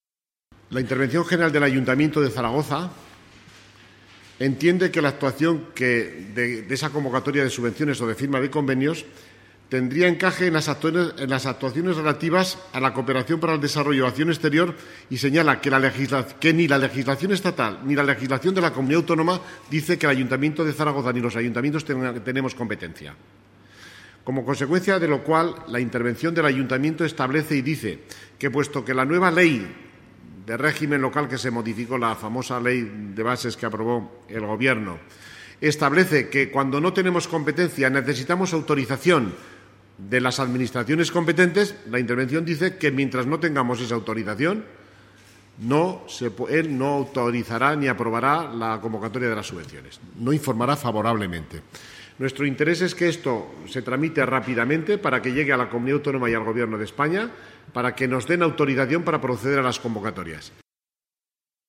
El portavz municipal, Fernando gimeno, ha manifestado al respecto: